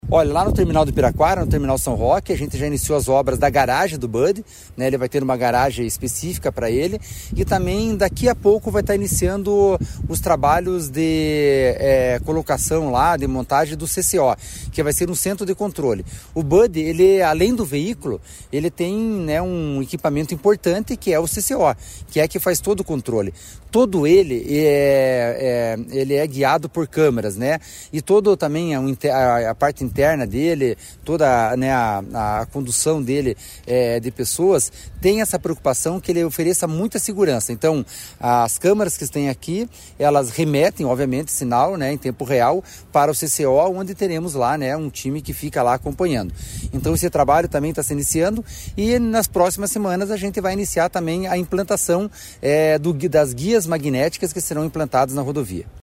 Sonora do diretor-presidente da Amep, Gilson Santos, sobre as obras do BUD na Região Metropolitana de Curitiba